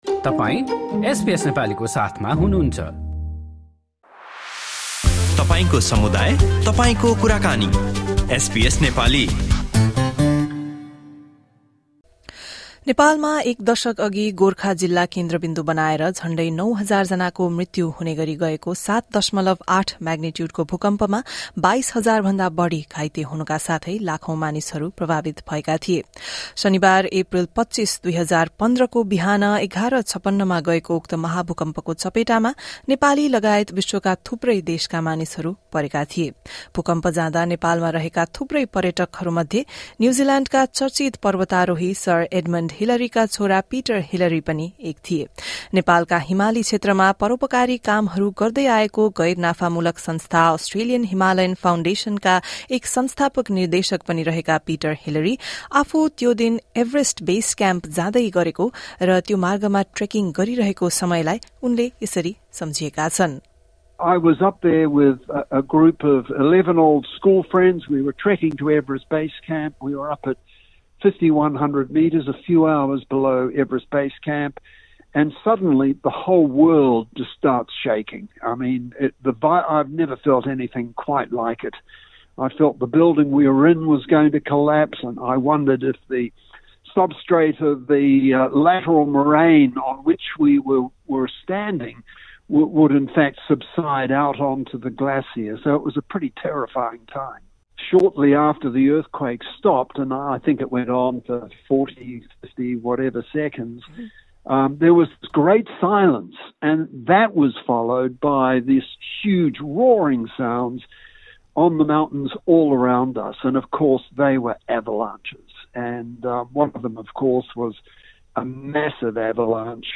This year marks a decade since a 7.8-magnitude earthquake struck Nepal, killing nearly 9,000 people and causing widespread devastation. Survivors, aid workers and witnesses spoke to SBS Nepali as they reflect on the impact and ongoing recovery.